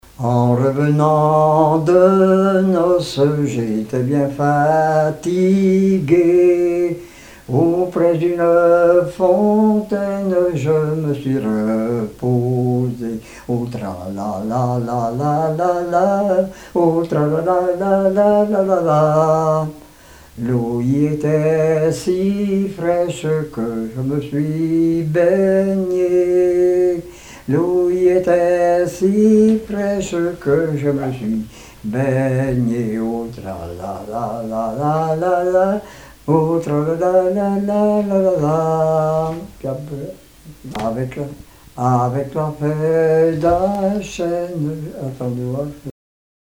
Genre laisse
Enquête Haut-Jura
Pièce musicale inédite